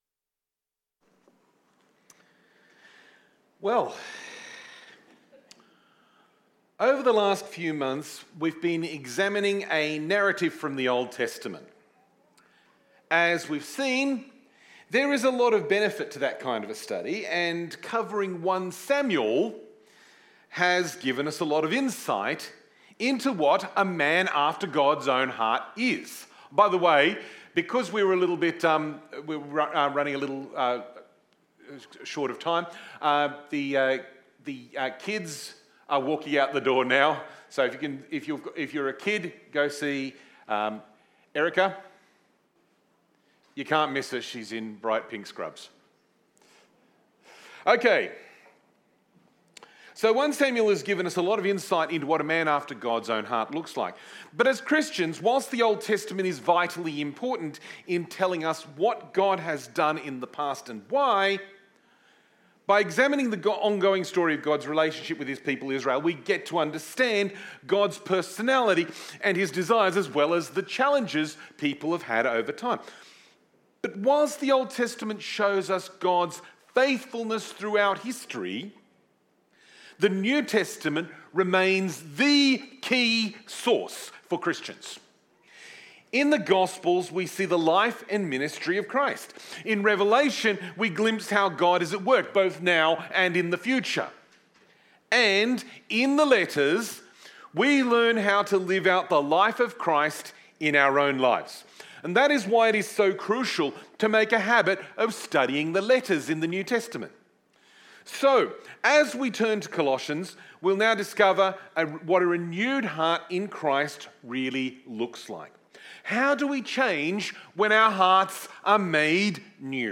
KPCC Sermons | Kings Park Community Church